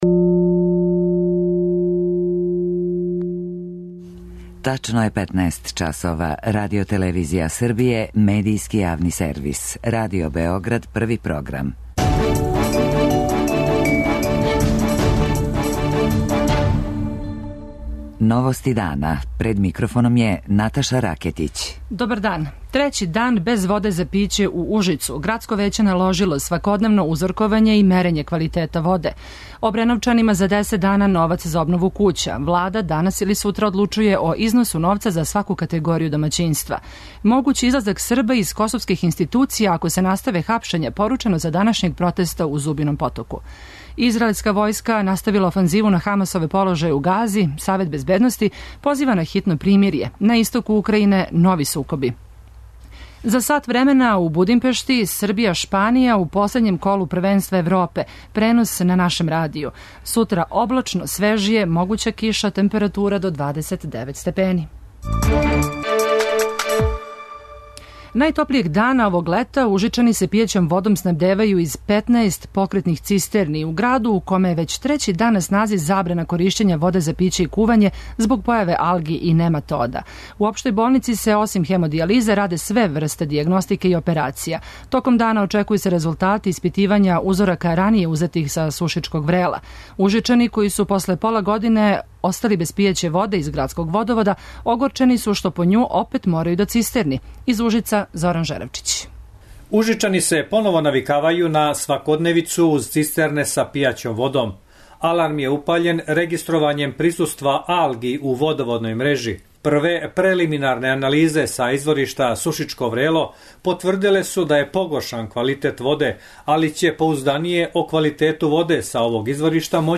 Наши репортери проверавају како се грађани Београда боре с високим температурама.
преузми : 15.07 MB Новости дана Autor: Радио Београд 1 “Новости дана”, централна информативна емисија Првог програма Радио Београда емитује се од јесени 1958. године.